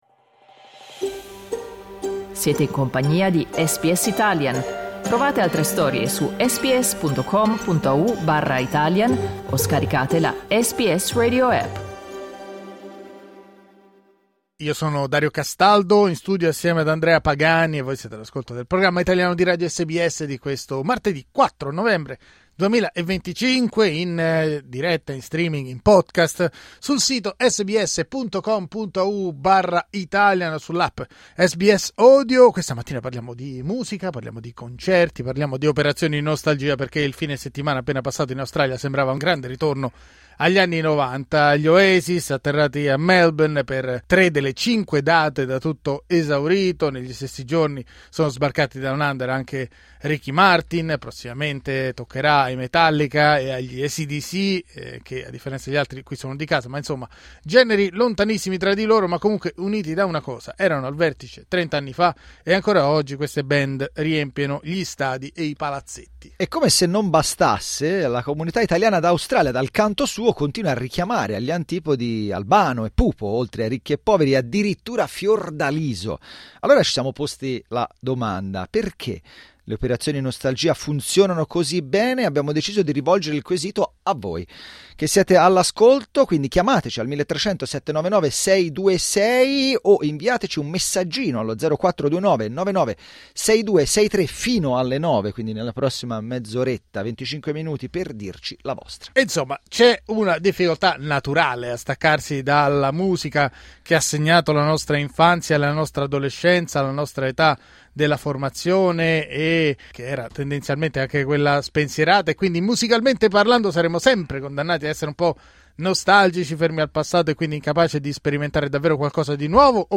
Clicca sul tasto "play" per ascoltare il nostro dibattito di martedì 4 novembre 2025 Ricky Martin durante un concerto nella Rod Laver arena.